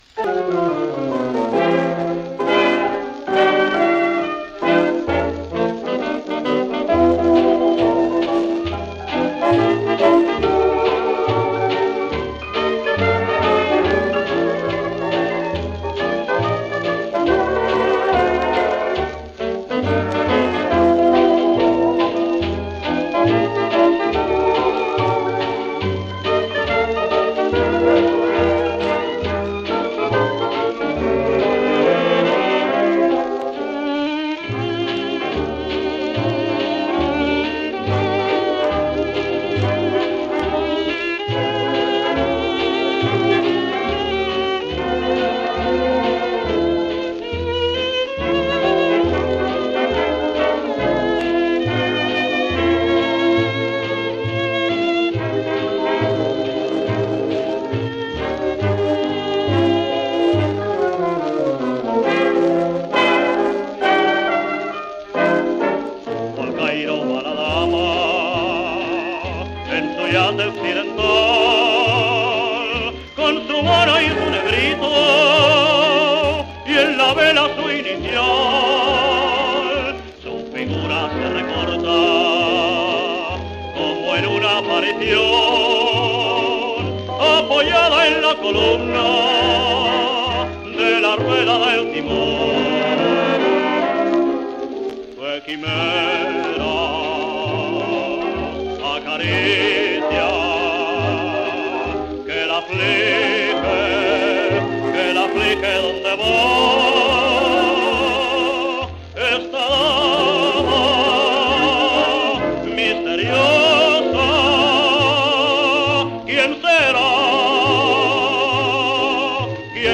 Foxtrot.